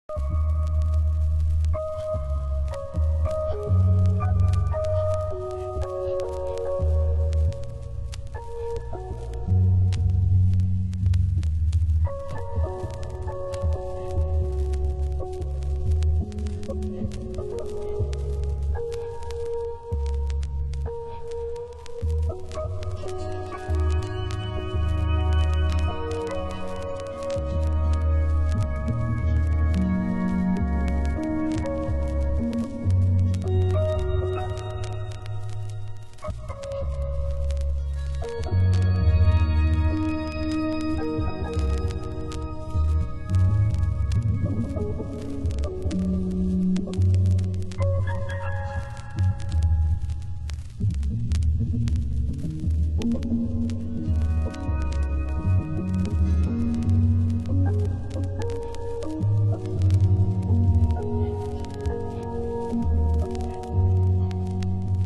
盤質：チリパチノイズ有　　ジャケ：良好